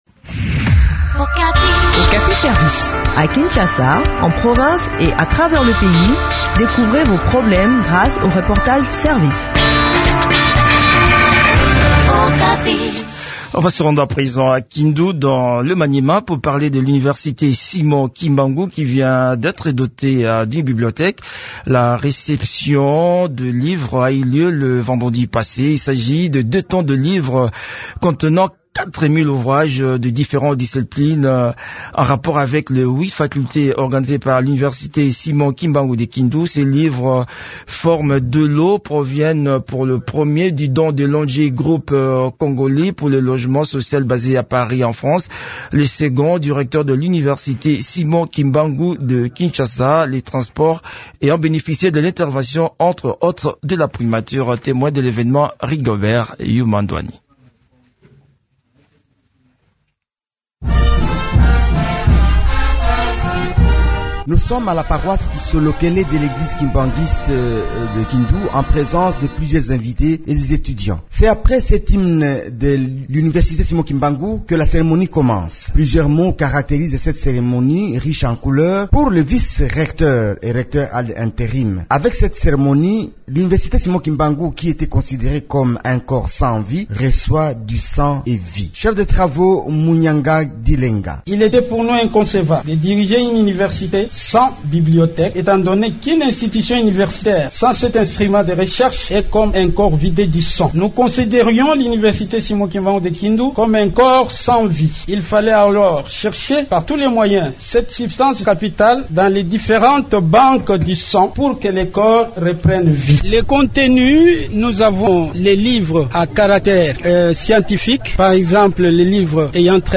Le point sur le fonctionnement de cette bibliothèque dans cet entretien